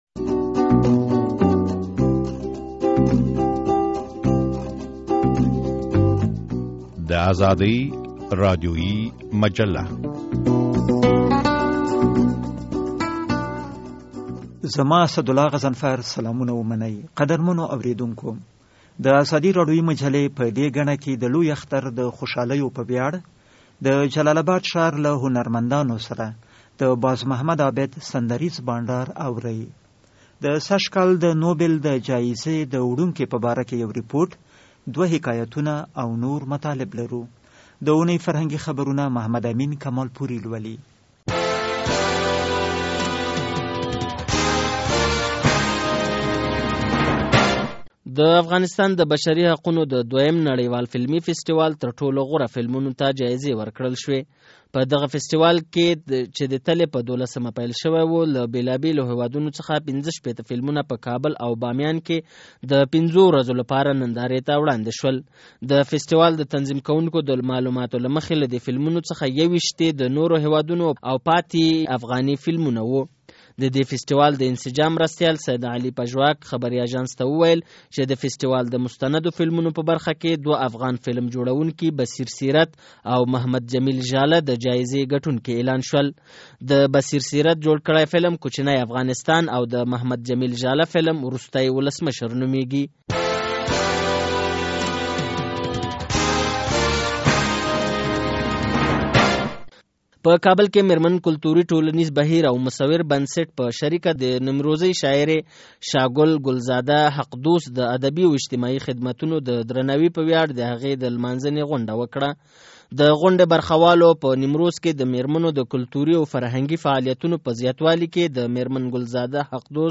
په جلال اباد کې د هنرمندانو اختریز بانډار